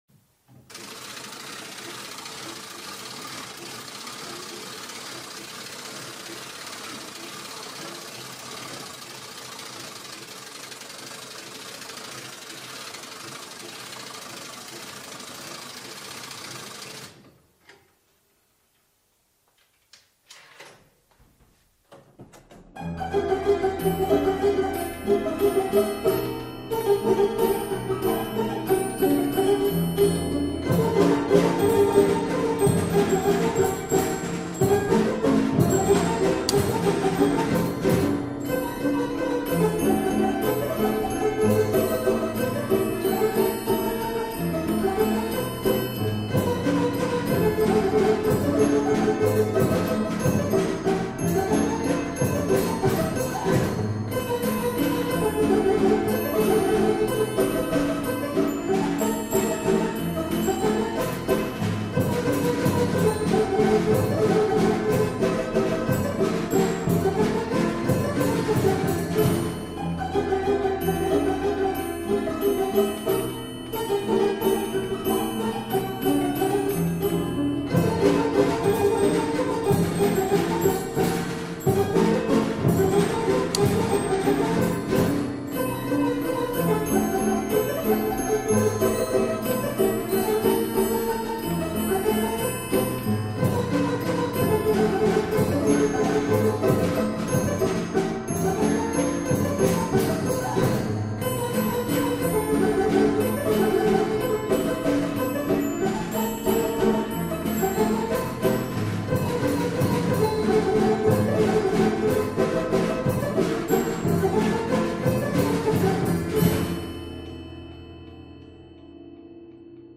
Piano Bastringue
Piano Mécanique ou "Bastringue" Magnan Frères
Fonctionnement à Manivelle et ressort, une deuxième manivelle plus petite permet de choisir le morceau. Déclenchement par monnayeur et bouton pressoir.
Un cylindre de grande dimension permet l'écoute de 10 airs d'1min30 chacun.